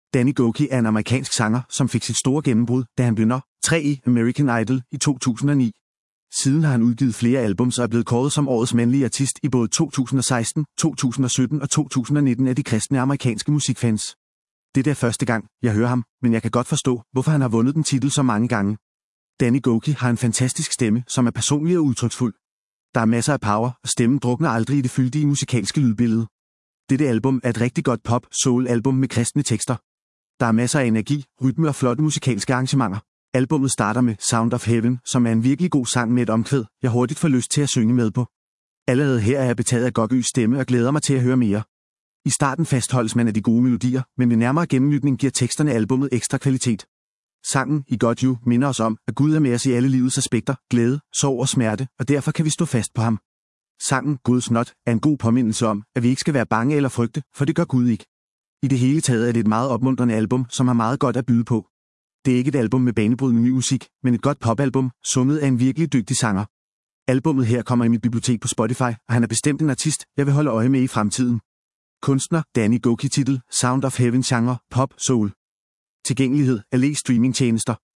Genre: pop/soul